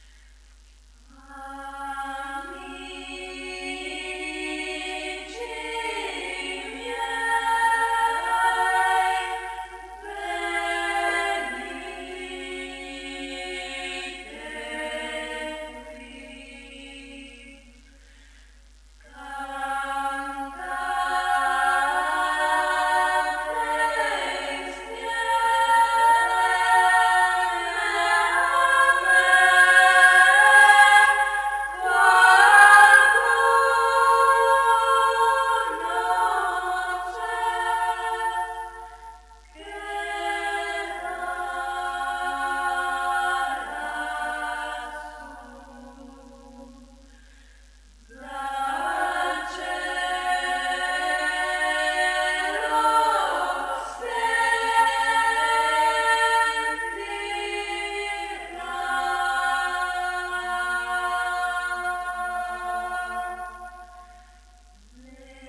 Coretto parrocchiale "Les Mariutines" di Tomba